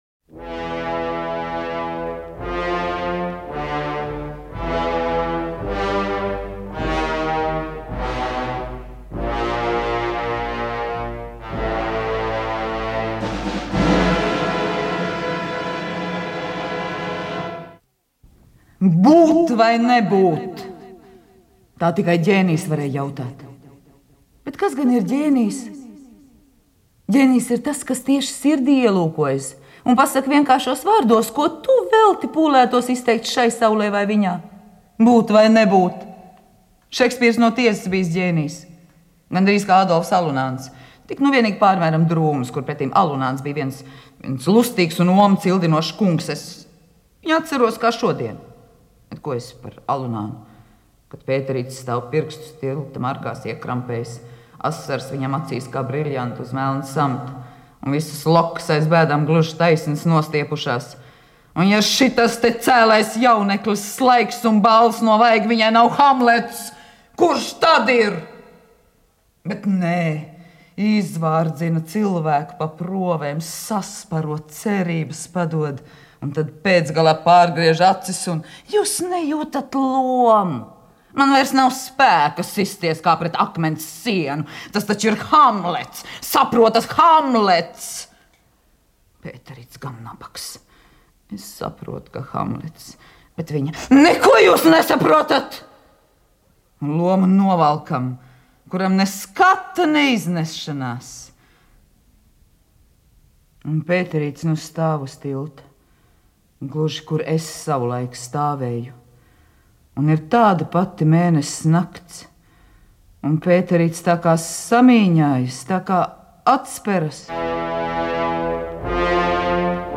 Vizmas Belševicas šausmu stāsta "Baltā sieva" radiolasījums.